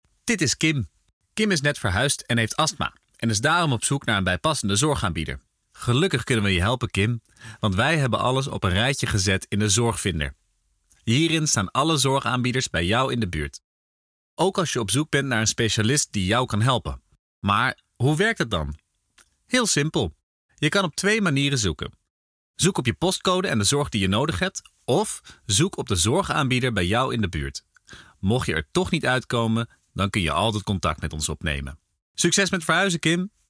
Actor, Director, Stage & Screenwriter, Voice actor
Anderzorg_LegtUit_VO_Script_5_V2.mp3